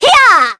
Sonia-Vox_Attack4.wav